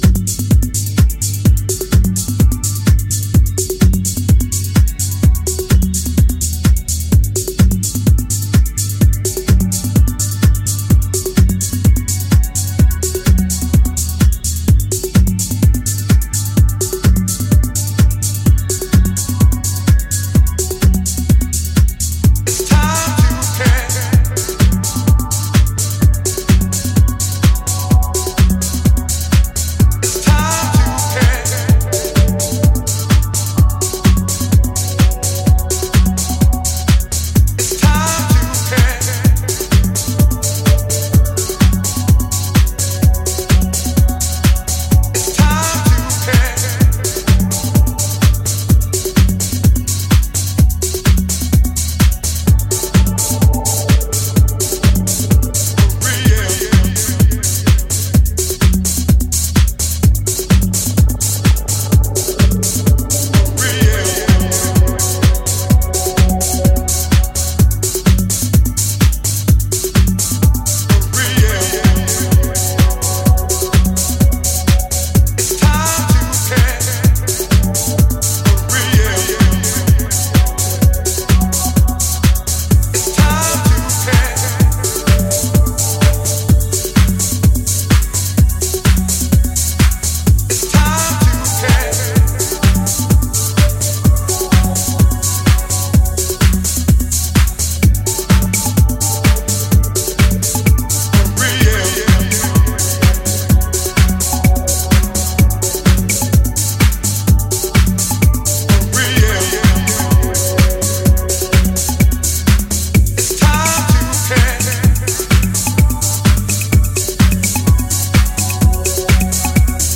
French deep house